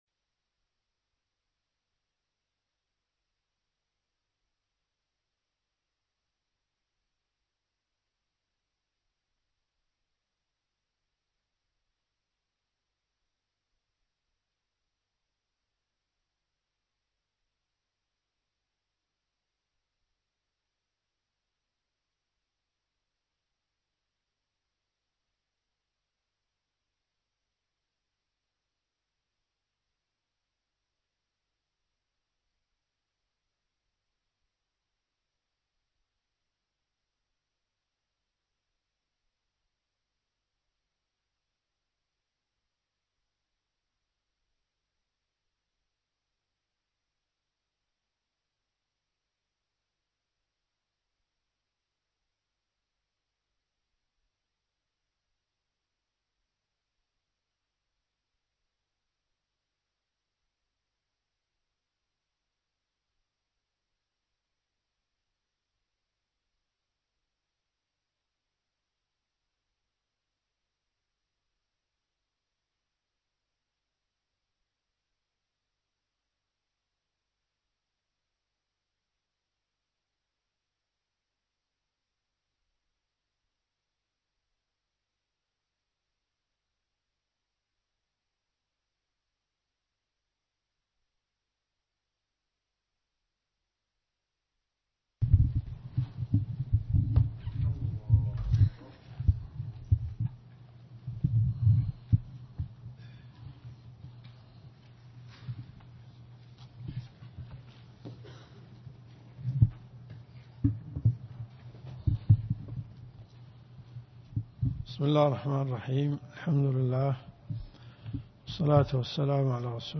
الرياض . حي الفلاح . مسسجد منيرة حمد الشبيلي
الرئيسية الدورات الشرعية [ قسم أحاديث في الفقه ] > بلوغ المرام . 1435 + 1436 .